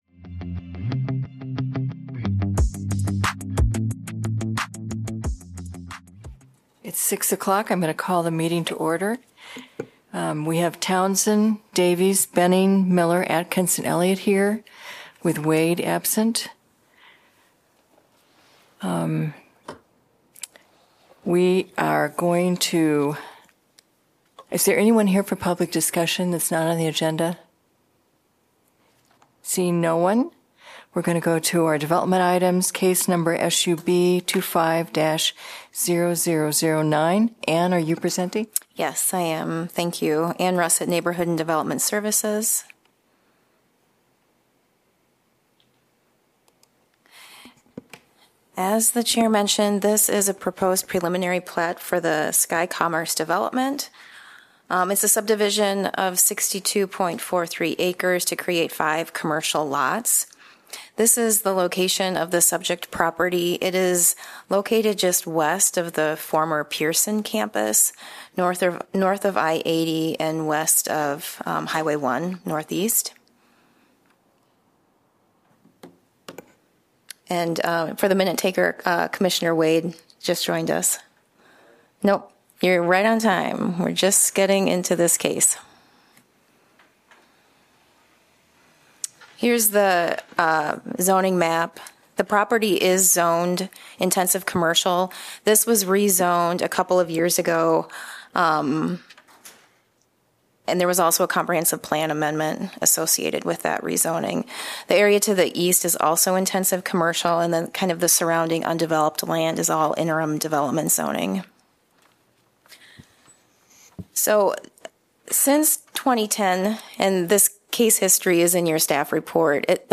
Regular semi-monthly meeting of the Planning and Zoning Commission.